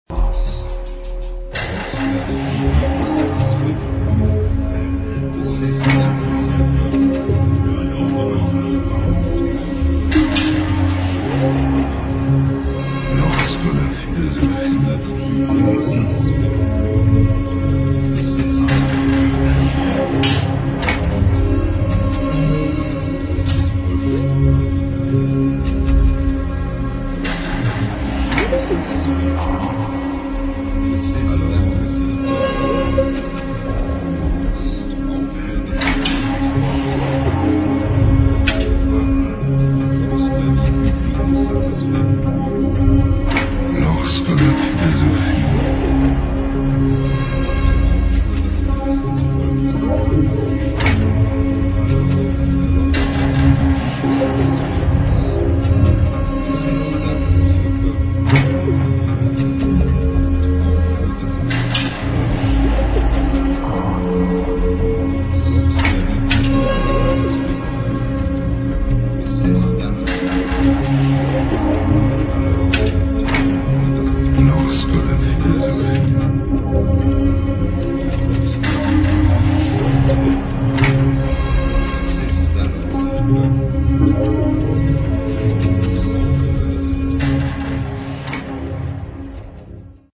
[ ELECTRONIC / EXPERIMENTAL / AMBIENT ]